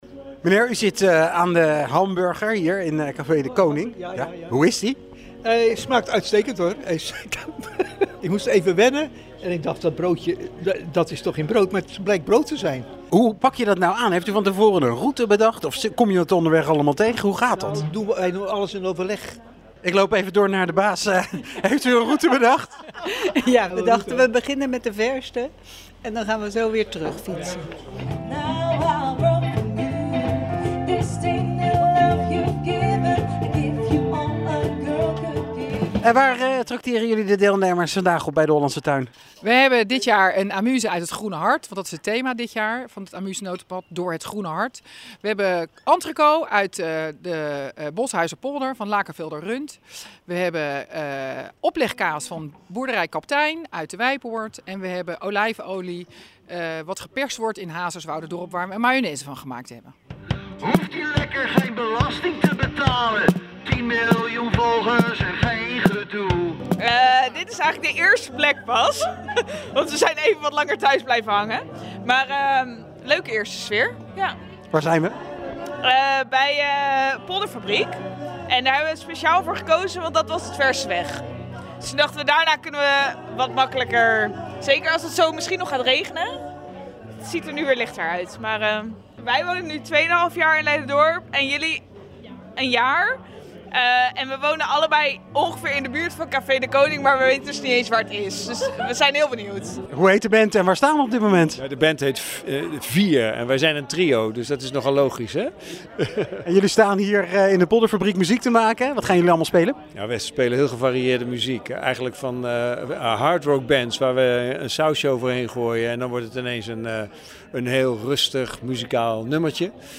De horeca in Leiderdorp was zondagmiddag weer even extra verbonden tijdens het ‘Amuse Notenpad’. Op een zelf te kiezen route konden deelnemers bij zeven gelegenheden genieten van een hapje, een drankje en live muziek.